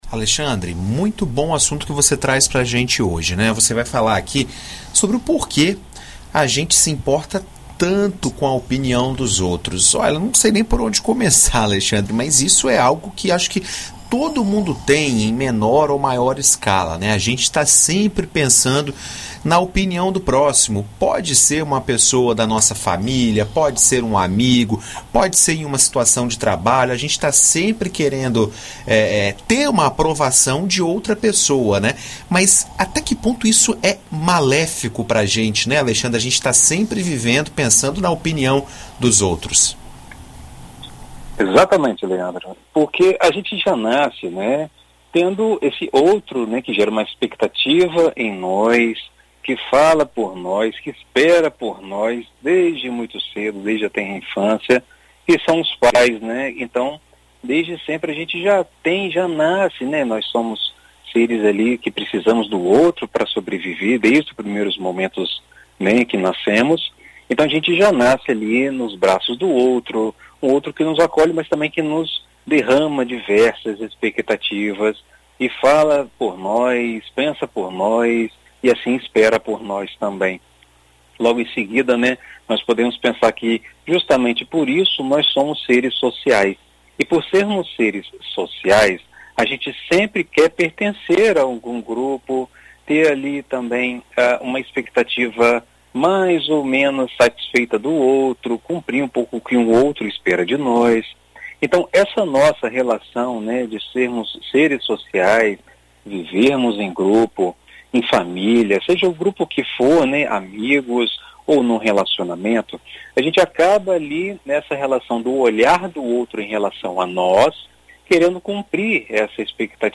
Em entrevista à BandNews FM Espírito Santo nesta segunda-feira